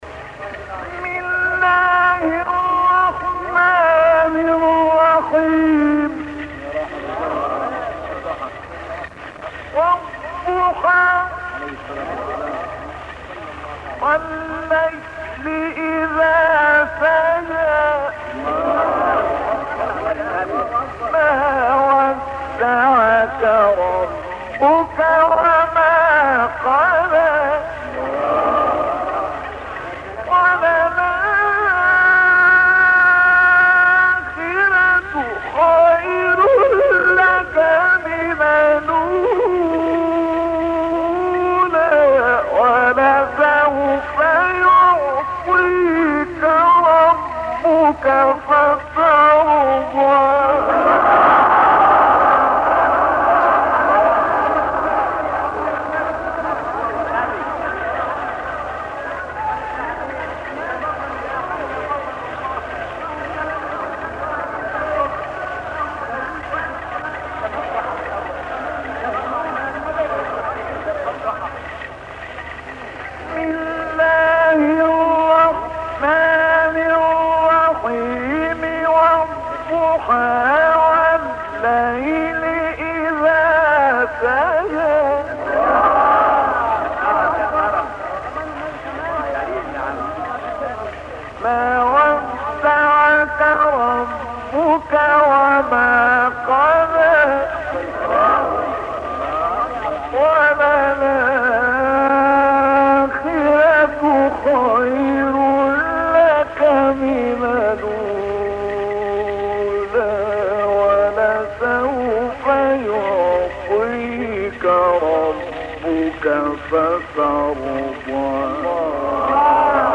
۳ تلاوت کمیاب از استاد «عبدالفتاح شعشاعی» + دانلود/ تلاوتی با نفَس کوتاه
در ادامه سه قطعه از تلاوت‌های استاد شعشاعی ارائه می‌شود.